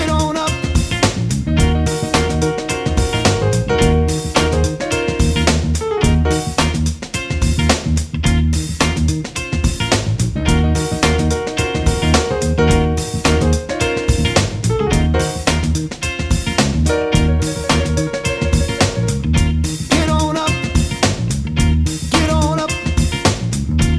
no Backing Vocals Soul